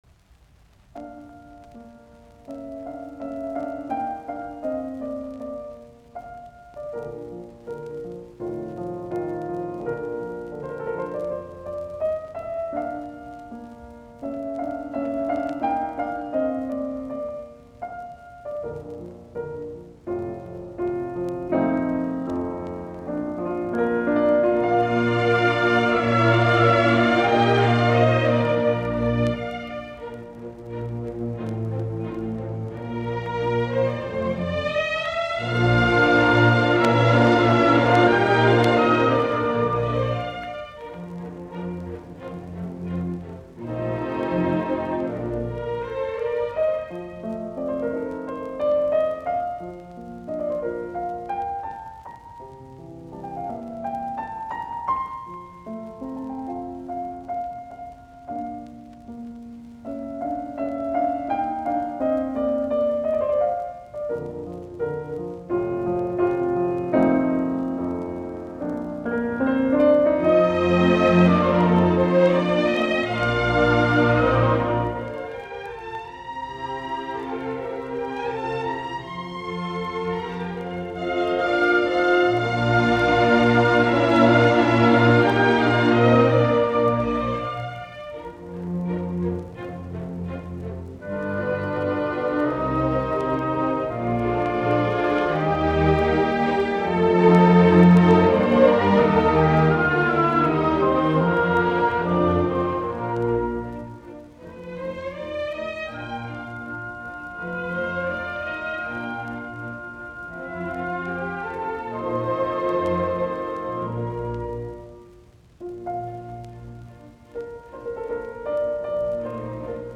Concerto No. 20 in D Minor for Piano and Orchestra, K. 466